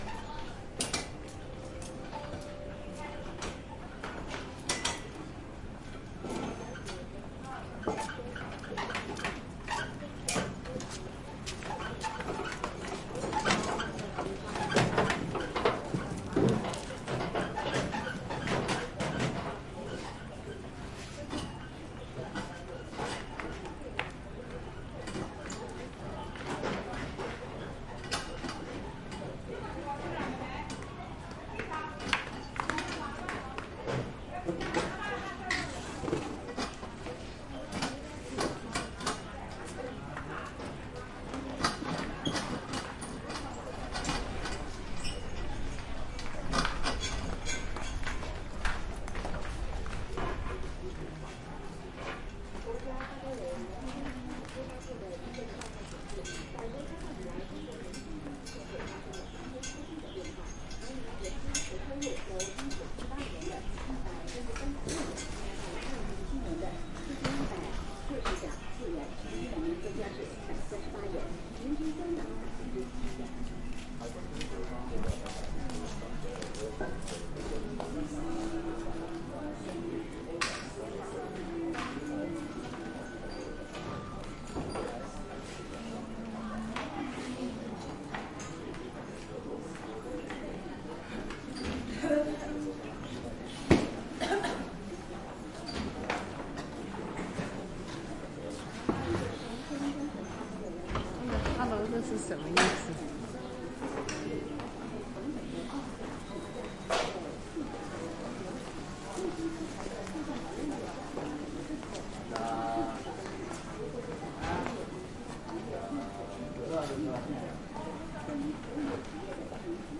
中国 " 市场散步 好的细节 平静的 少的BG 交通的声音 活动的步骤 北京，中国
描述：市场步行好细节平静安静少bg交通声音步骤活动北京，China.flac
Tag: 市场 中国 活动 平静 步骤 细节 安静 声音